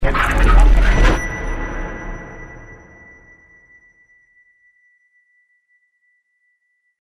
Eyes_jumpscare_sound_(Post-hunt_upd).mp3